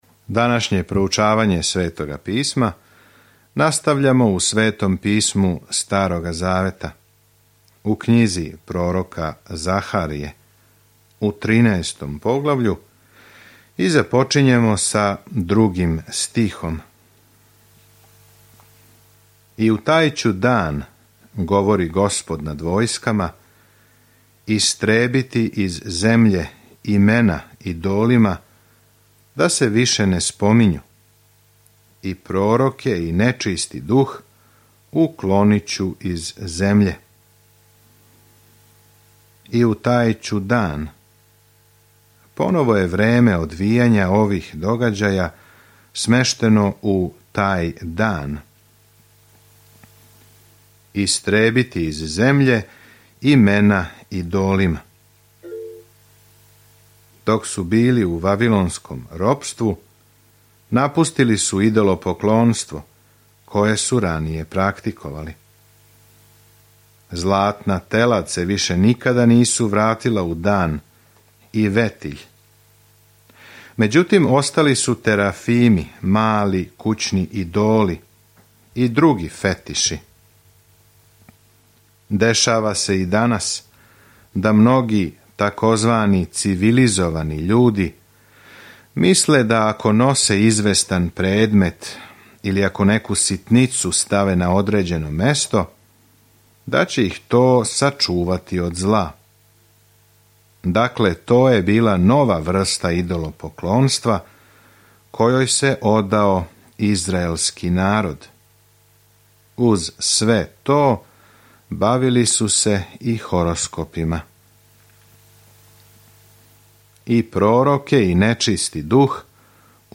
Scripture Zechariah 13:2-7 Day 39 Start this Plan Day 41 About this Plan Пророк Захарија дели визије о Божјим обећањима да ће људима дати наду у будућност и подстиче их да се врате Богу. Свакодневно путујте кроз Захарију док слушате аудио студију и читате одабране стихове из Божје речи.